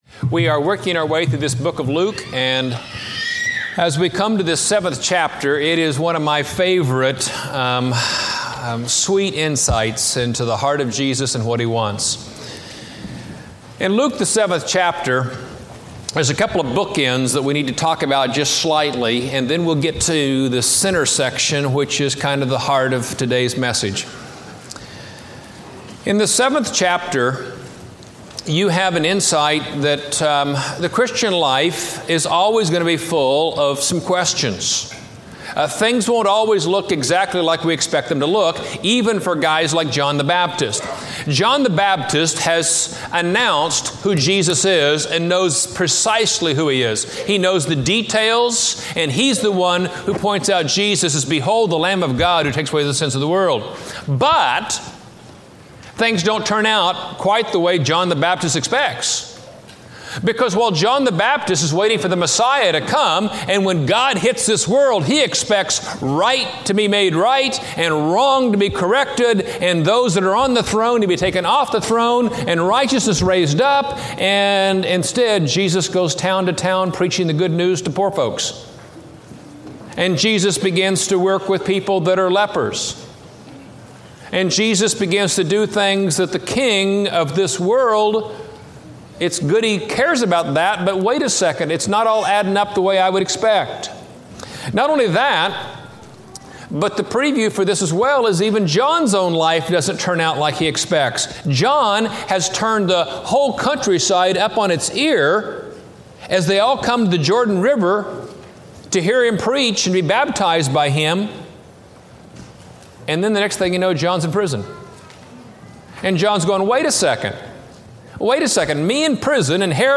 An Uncompromising Life, Like John Preached at College Heights Christian Church January 22, 2006 Series: Luke, 2006 Scripture: Luke 7-8 Audio